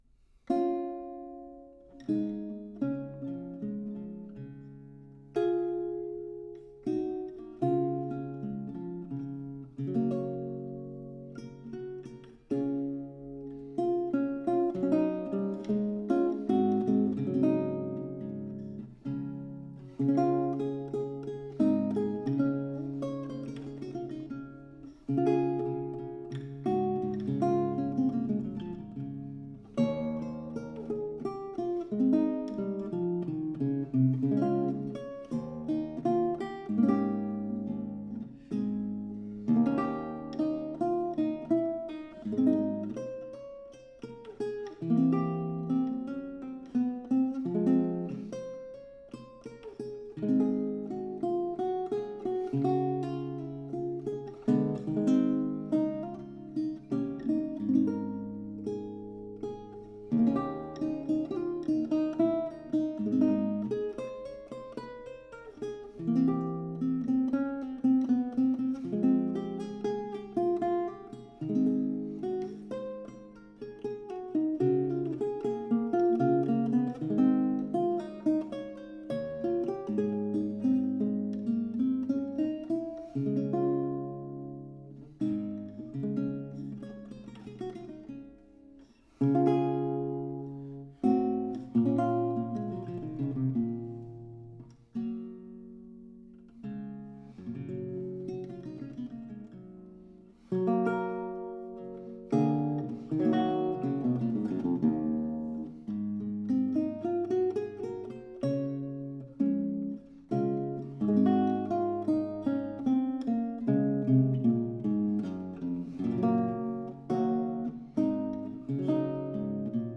Gitarre von Georg Thielemann, Berlin, 1806, Kat.-Nr. 5797